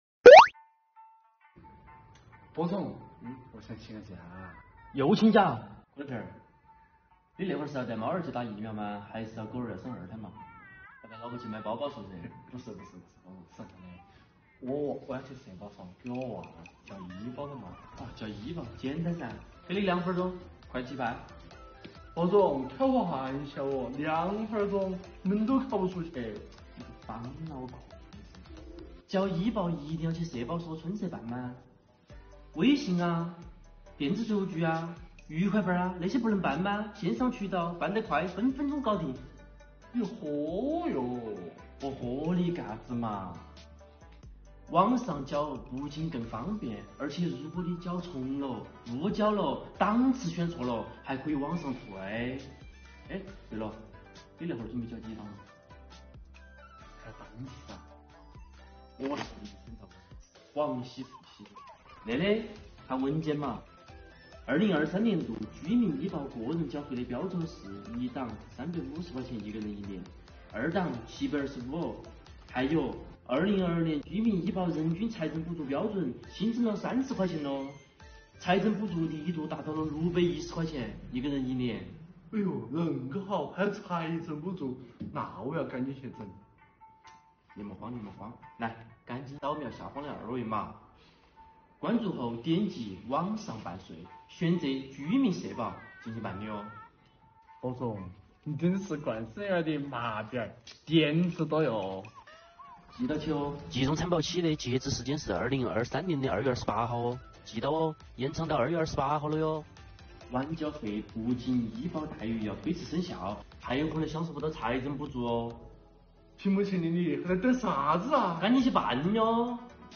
重庆言子来了⑤ |2023年度城乡居民基本医保集中缴费延期了